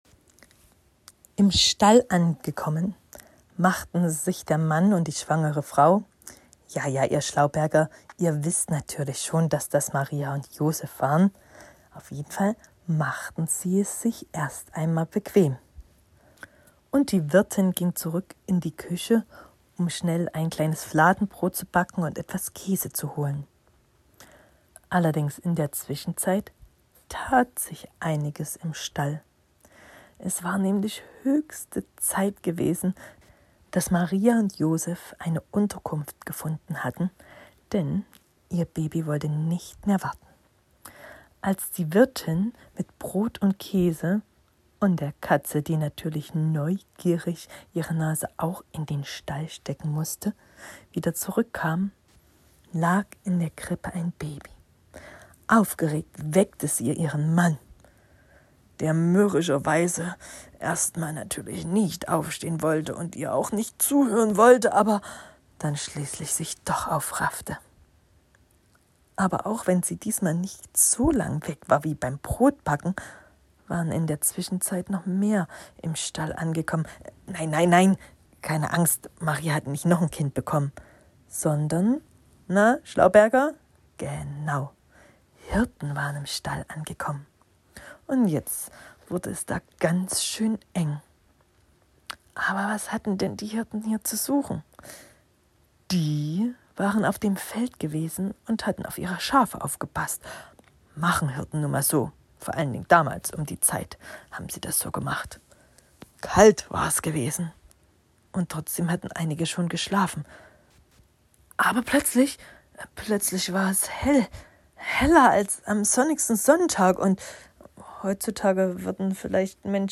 Hörbuchgeschichten